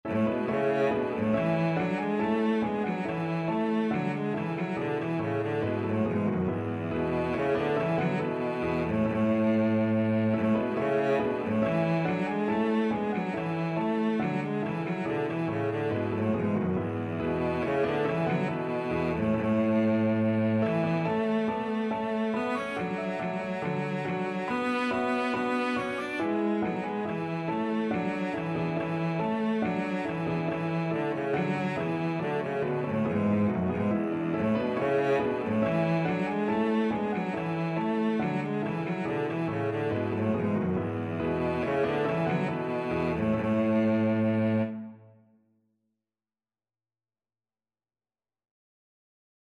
Free Sheet music for Cello
Cello
A major (Sounding Pitch) (View more A major Music for Cello )
4/4 (View more 4/4 Music)
E3-D5
Classical (View more Classical Cello Music)